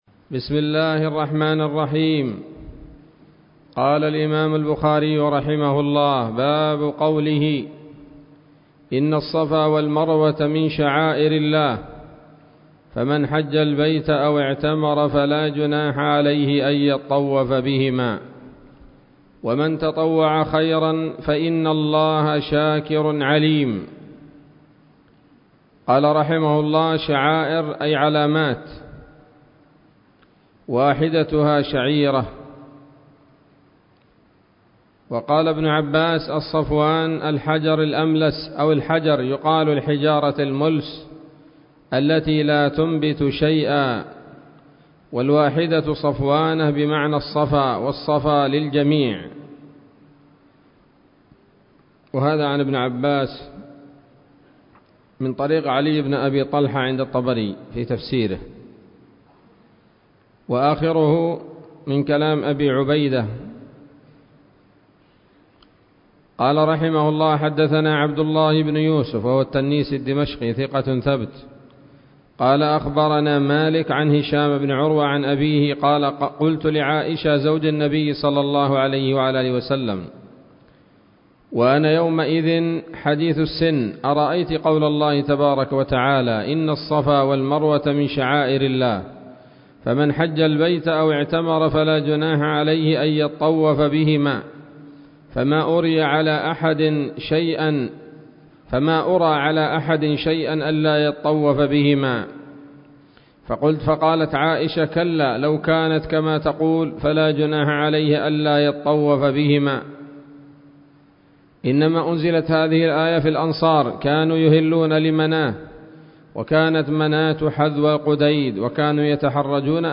الدرس الثامن عشر من كتاب التفسير من صحيح الإمام البخاري